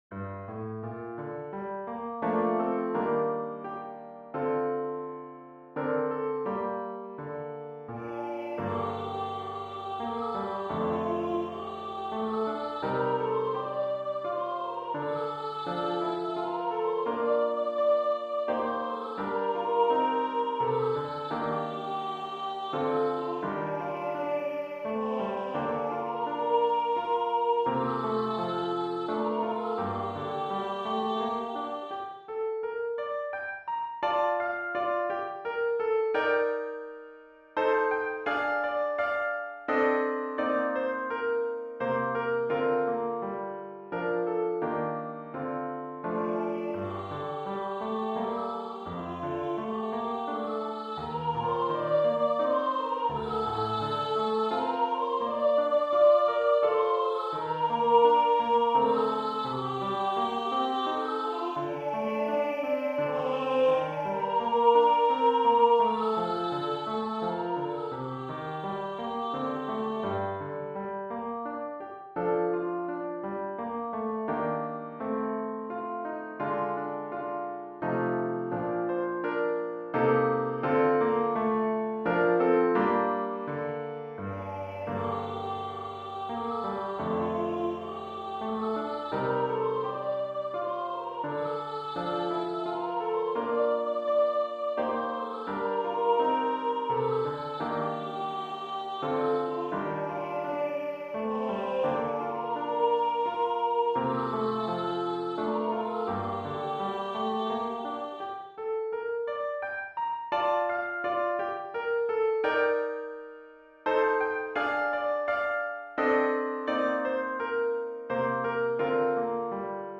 Scored for: Solo Voice and Piano A new series for 2019.